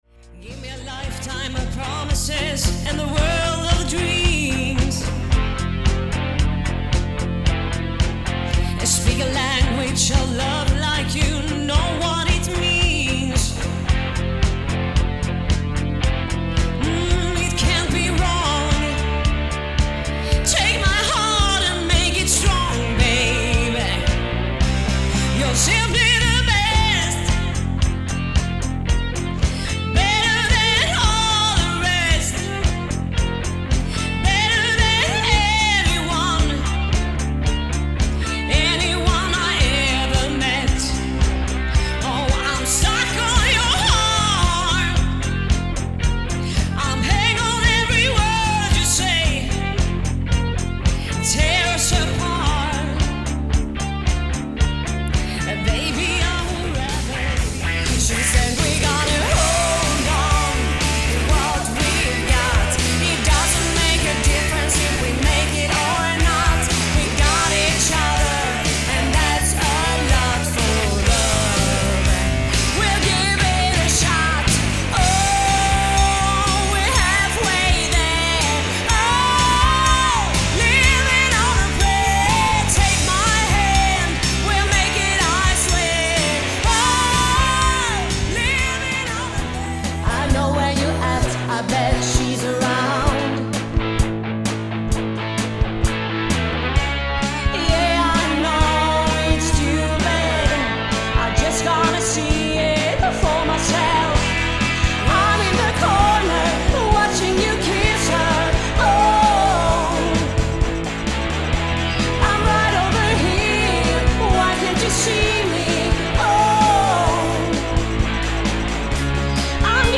ETT PARTYBAND FÖR DEN KRÄSNE?
• Coverband
• Hårdrock
• Soul/Disco